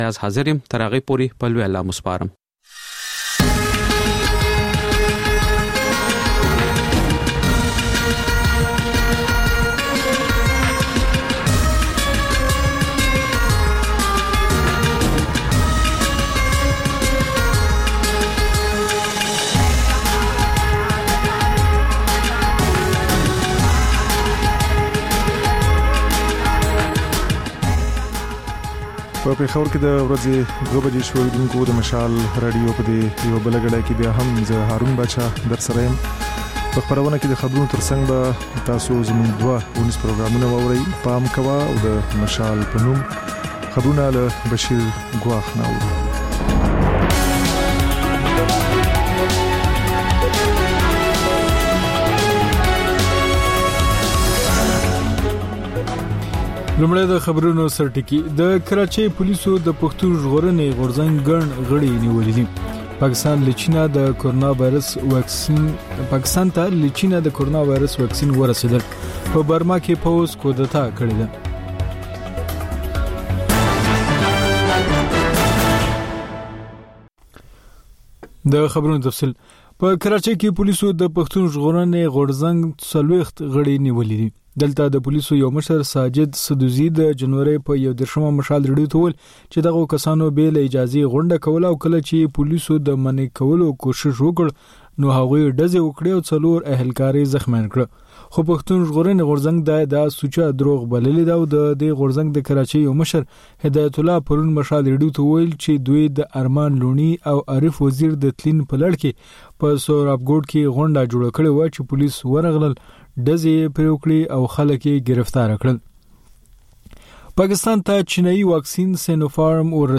د مشال راډیو دویمه ماسپښینۍ خپرونه. په دې خپرونه کې تر خبرونو وروسته بېلا بېل رپورټونه، شننې، مرکې خپرېږي.